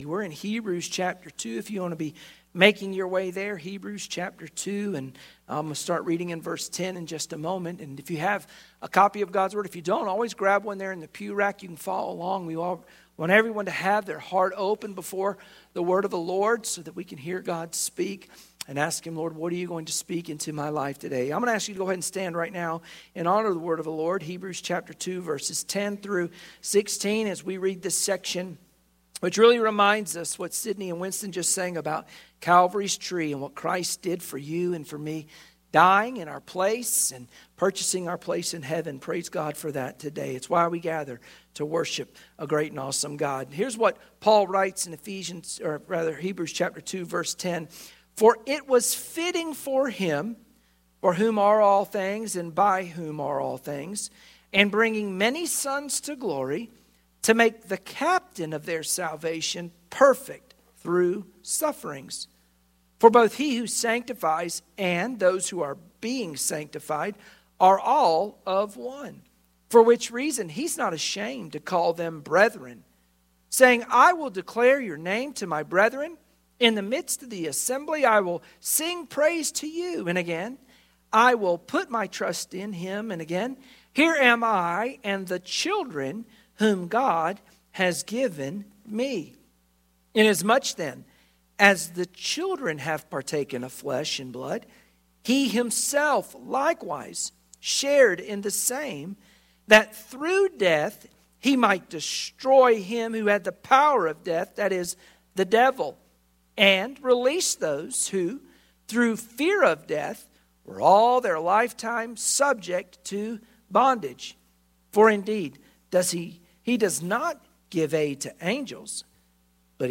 Sunday Morning Worship Passage: Hebrews 2:10-16 Service Type: Sunday Morning Worship Share this